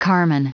Prononciation du mot carmine en anglais (fichier audio)
Prononciation du mot : carmine